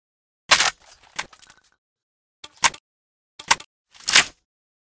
toz34_reload.ogg